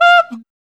3007R MONKEY.wav